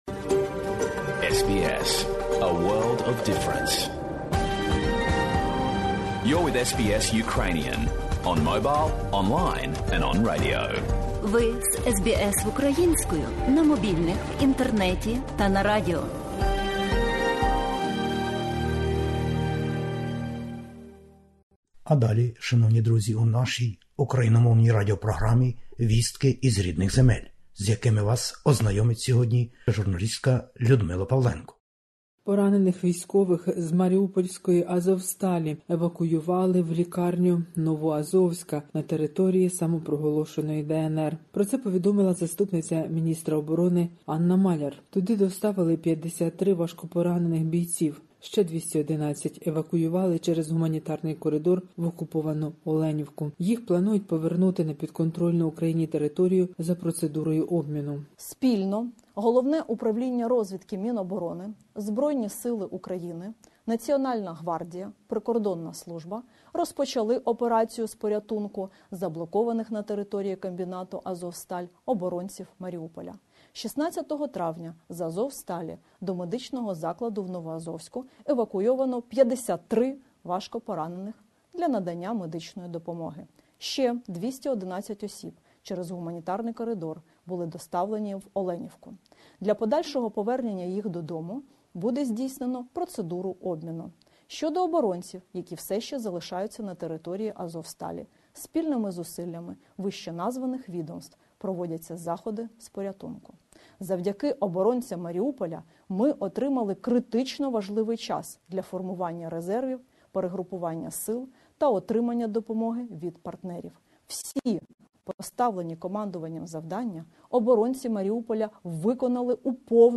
SBS новини українською - 17/05/2022
Добірка новин із воюючої України-героїні. Поранених із Маріуполя евакуювали на підконтрольну територію російської армії.